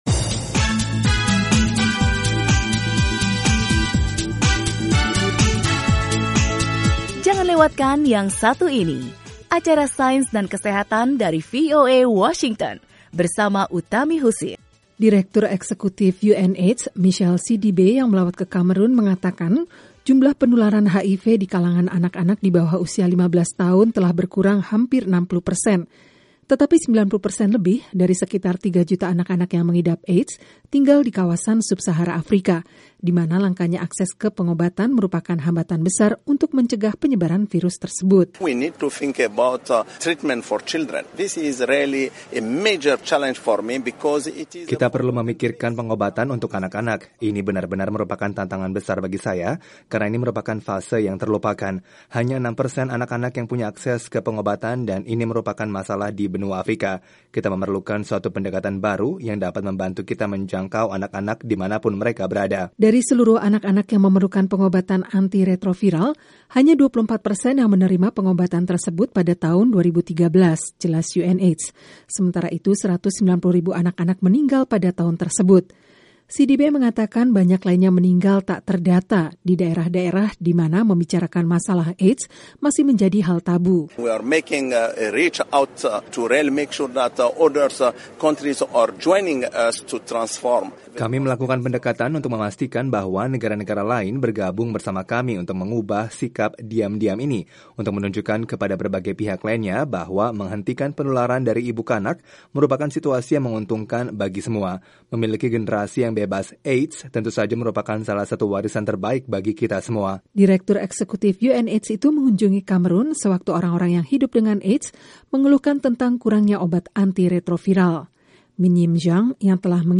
Sains & Kesehatan